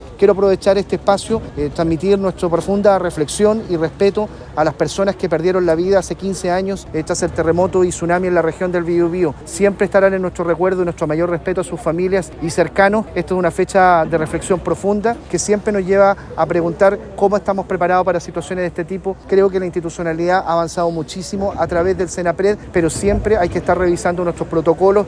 El delegado Presidencial de la región del Bío Bío, Eduardo Pacheco, señaló que se están invirtiendo recursos para los casos pendientes de reconstrucción en la región, pero en especial, dijo, hoy es una fecha de reflexión.